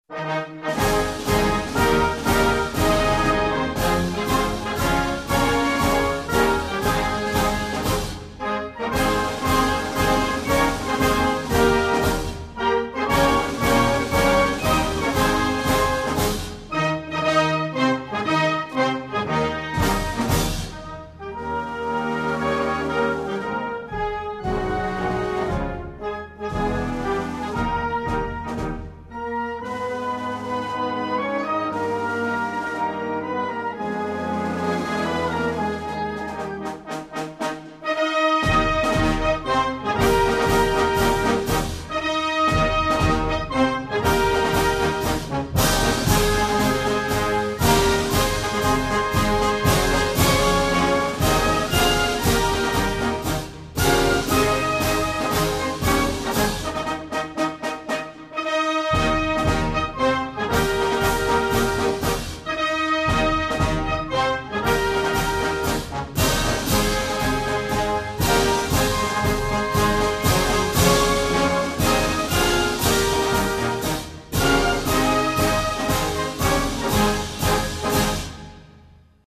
I'll play a national anthem, you tell me which country has it played before their World Cup games.
Anthem 3